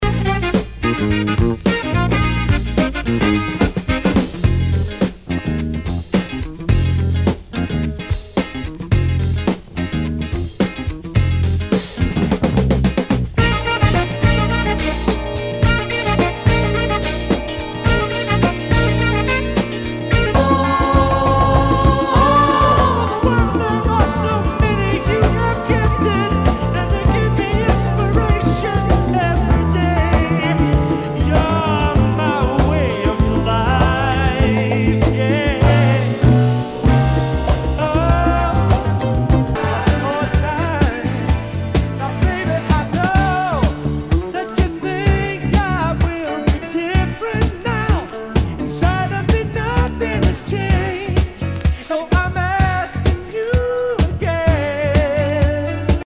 SOUL/FUNK/DISCO
全体にチリノイズが入ります